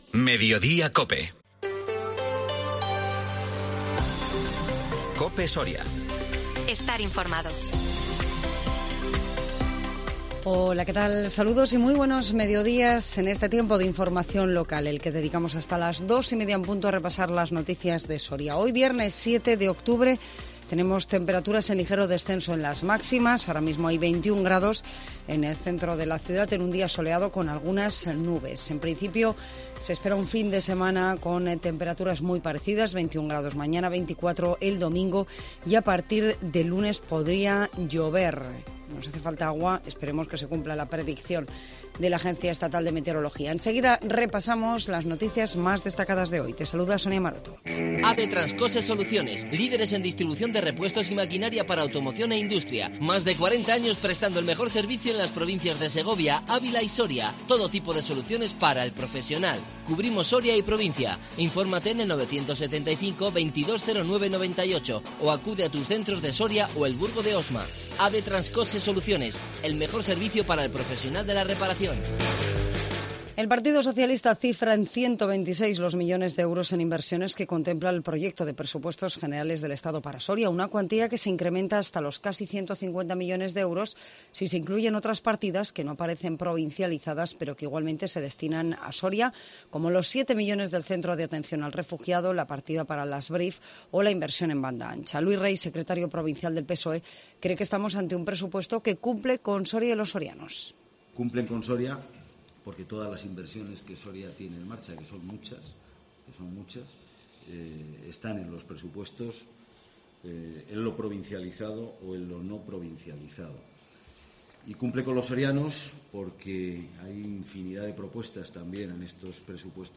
INFORMATIVO MEDIODÍA COPE SORIA 7 OCTUBRE 2022